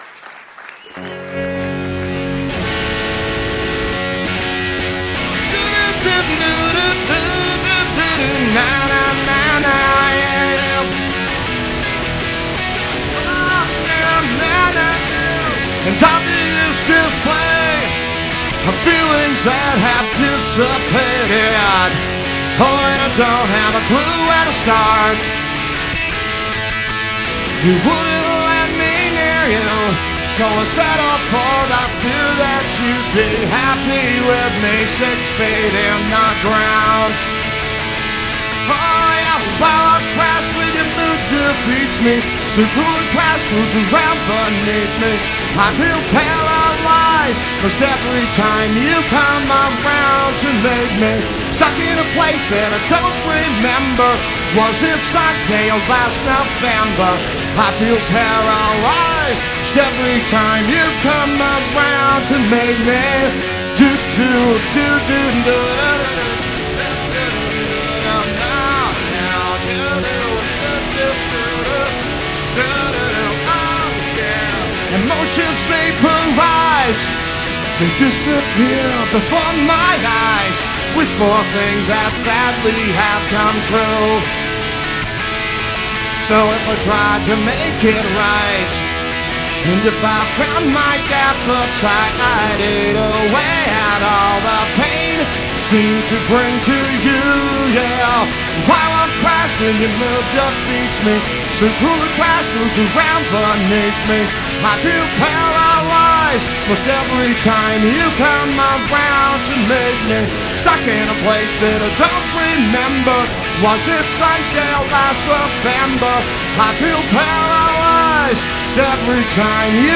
solo from World Cafe Philadelphia on January